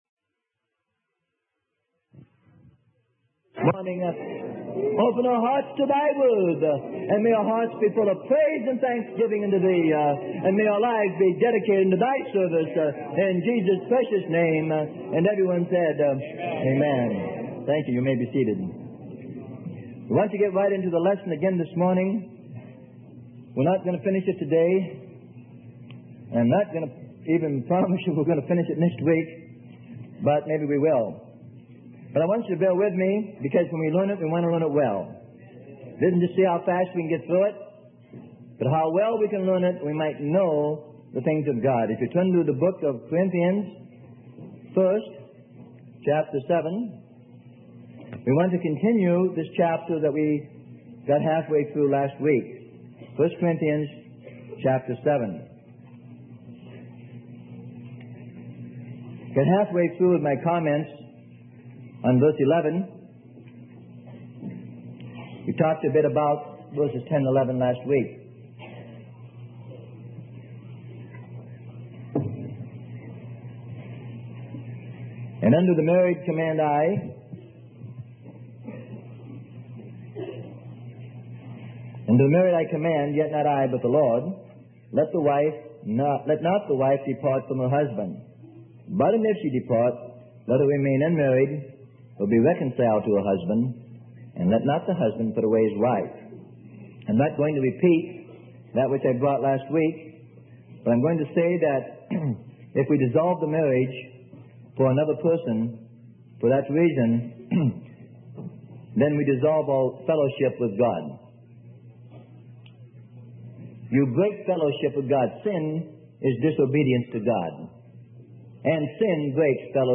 Sermon: Divorce and Remarriage - Part 7 - Freely Given Online Library